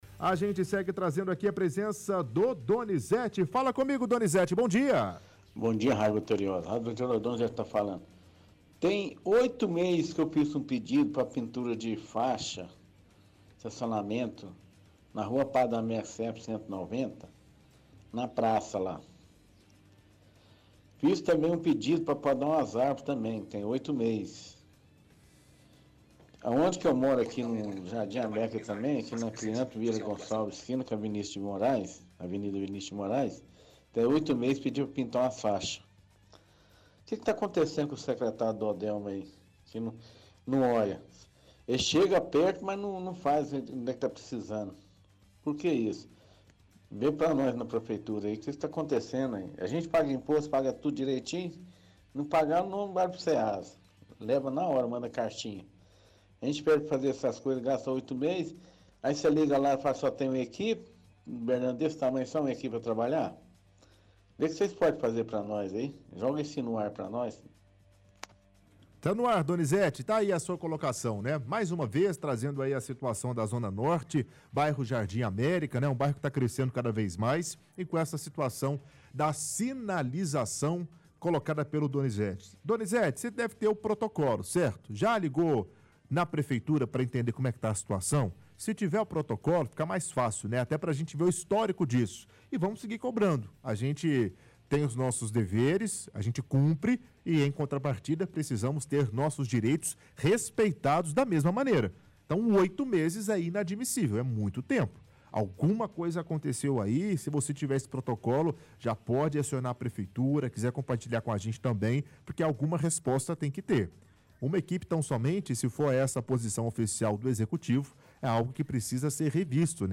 – Ouvinte diz que tem oito meses que pediu por pintura de faixas e corte de árvore no bairro Jardim América que até o momento não foi feito.